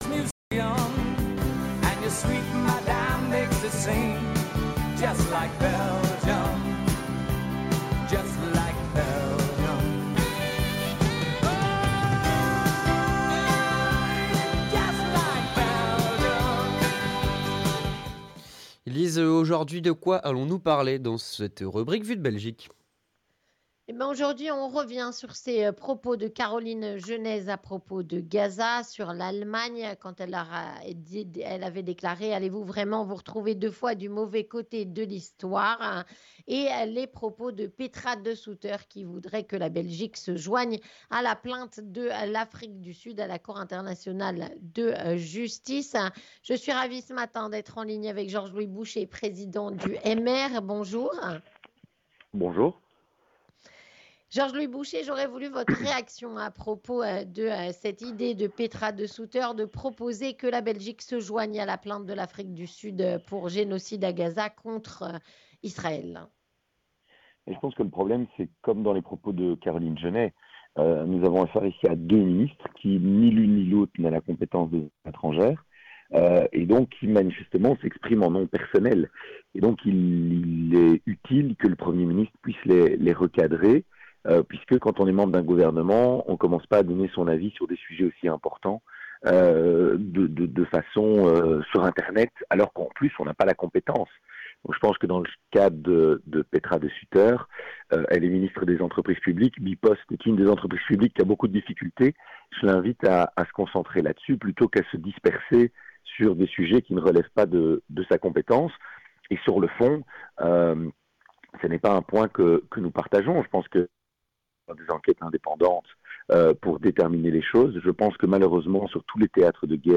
Avec Georges Louis Bouchez, président du MR